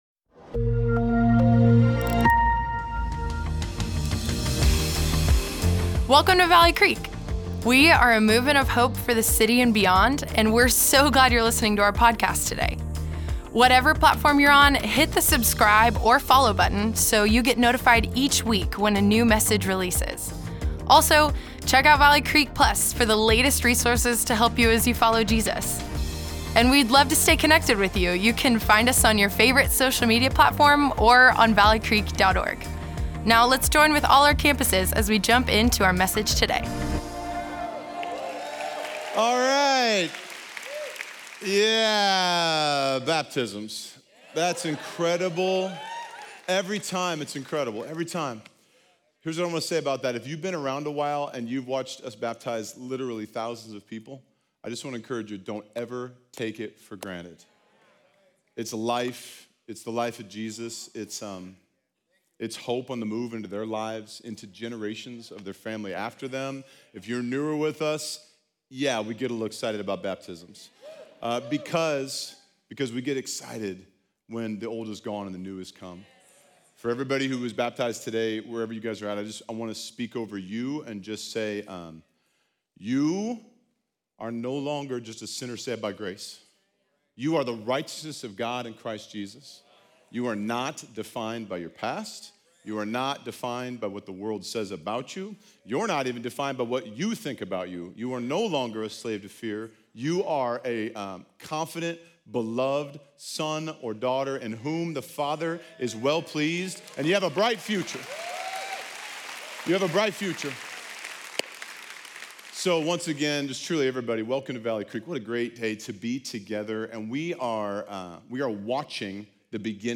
Valley Creek Church Weekend Messages